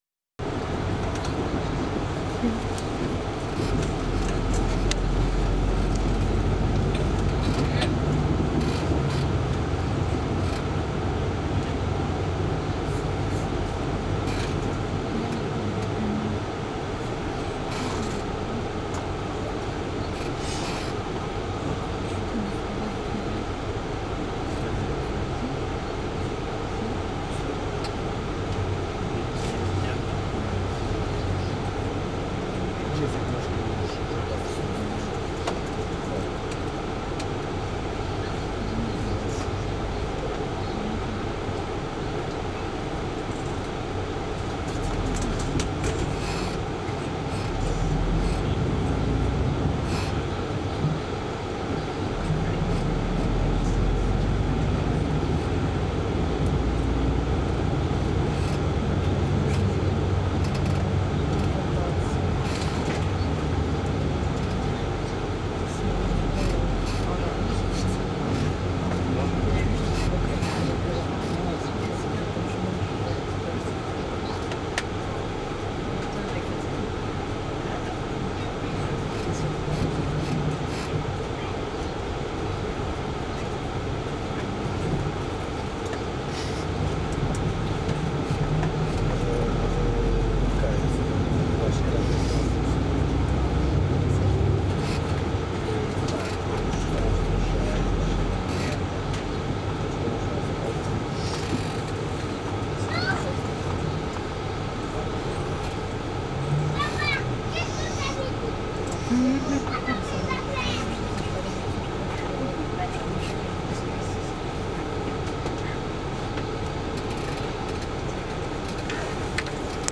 2020-01-02 13 uhr im Bus (Audio + Video)
Bangkok - Koh Tao (Fieldrecording Audio, Foto, Video)
Audio: aufgenommen mit Zoom H6 + Rode NT4 (24Bit/48kHz)